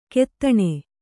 ♪ kettaṇe